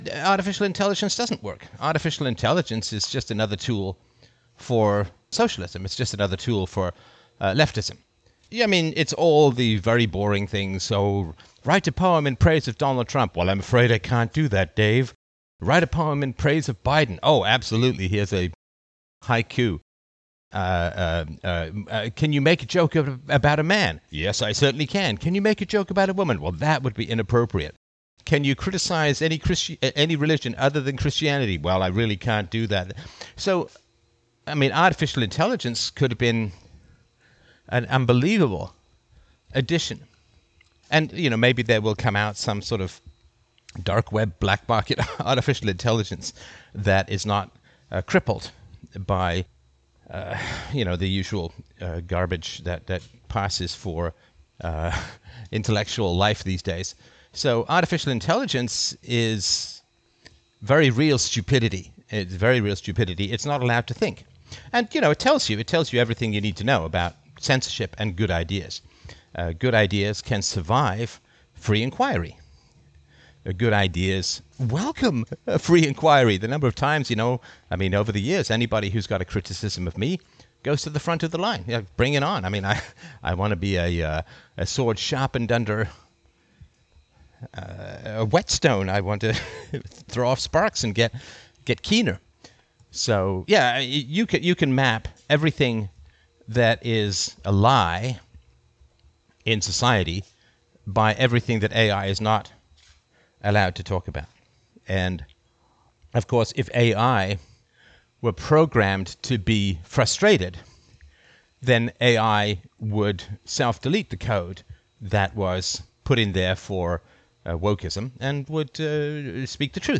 Philosopher – and former Chief Technical Officer and software expert – Stefan Molyneux discusses how mankind has tragically maimed the first digital god he has created... From a Freedomain live stream, 8 April 2023.